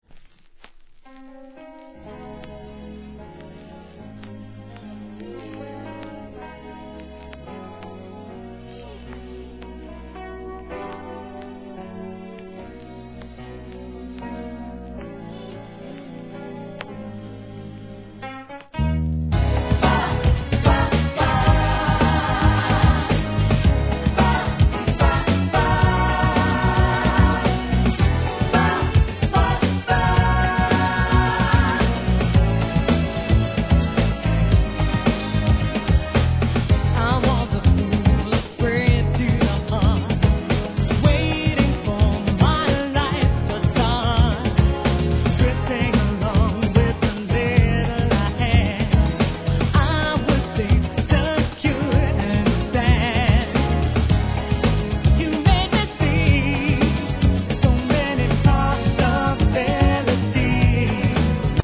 HIP HOP/R&B
ロンドン出身の女性シンガー